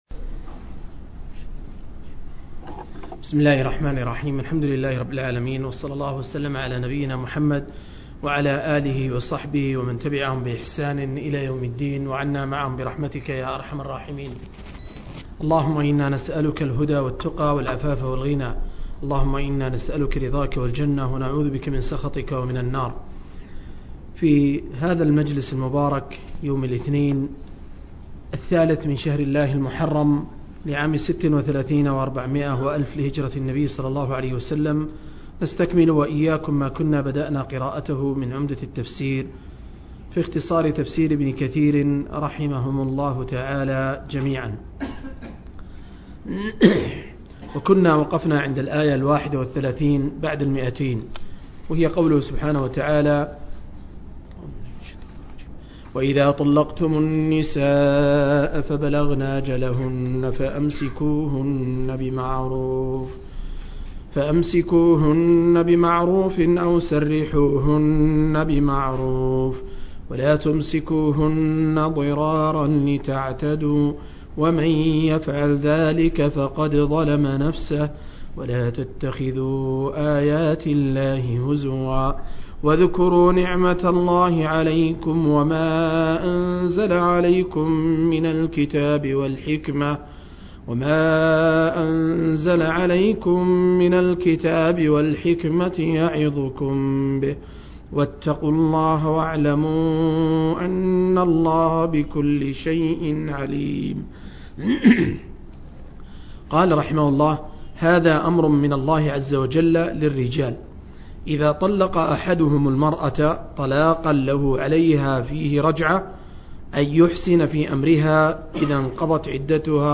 047- عمدة التفسير عن الحافظ ابن كثير – قراءة وتعليق – تفسير سورة البقرة (الآيتين 232-231)